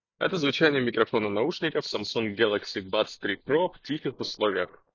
Samsung Galaxy Buds 3 Pro — Микрофон:
Звучание микрофона Samsung Galaxy Buds 3 Pro на 8 из 10  — немного хуже, чем у Apple Airpods Pro 2.
В тихих условиях: